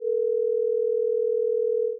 scene-2-ring.mp3